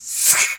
pgs/Assets/Audio/Animal_Impersonations/snake_2_attack_01.wav at master
snake_2_attack_01.wav